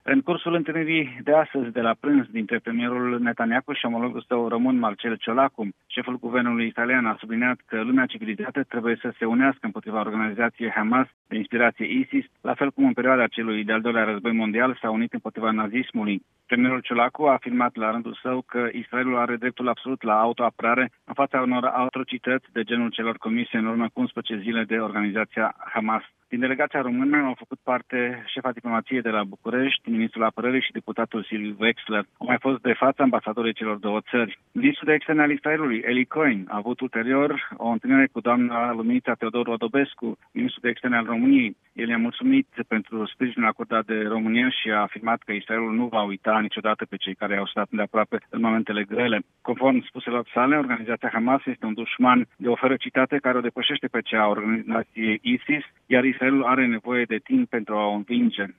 Transmite din Israel